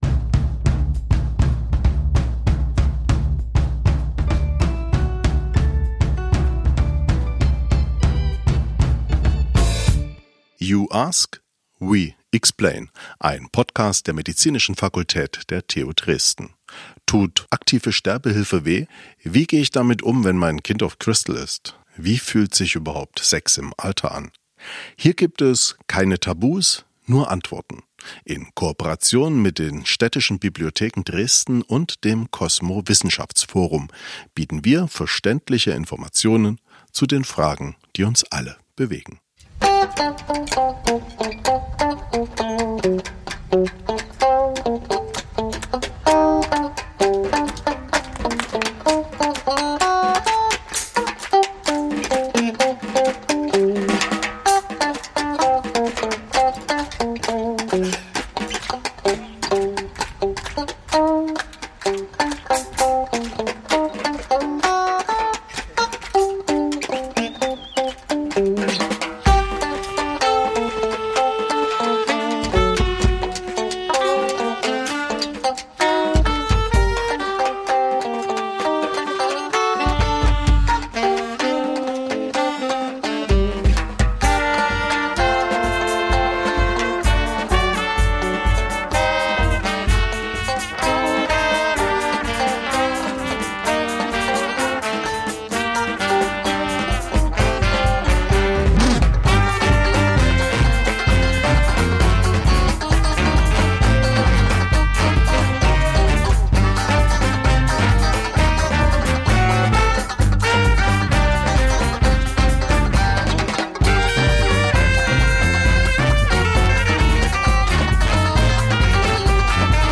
Dazu waren wir auf dem Neustädter Markt im Gespräch mit unseren Expert:innen:  Dr. med. habil.
Musikalische Begleitung von der Banda Comunale Geben Sie uns ihr Feedback- einfach, schnell und anonym.